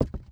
Footstep_Wood 05.wav